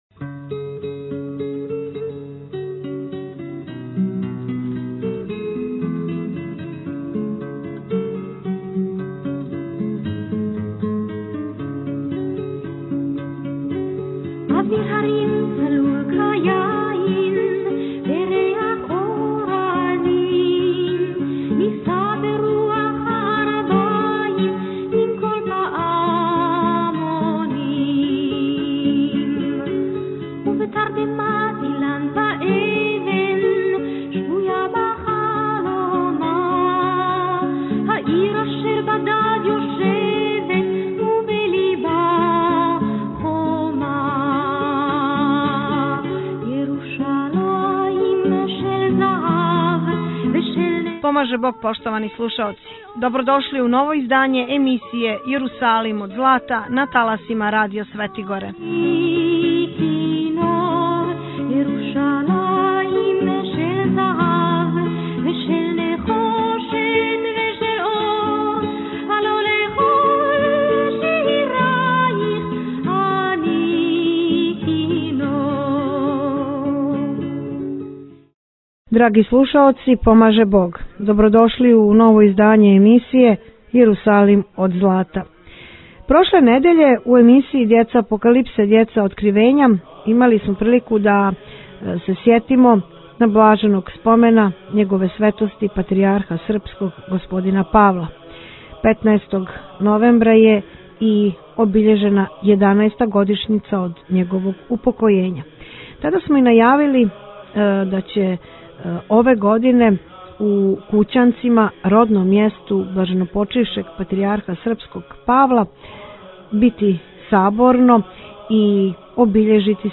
Novomucenici Prebilovacki Liturgija i predavanje; Опис: Емисија-Јерусалим од злата Тип: audio/mpeg Преузмите аудио датотеку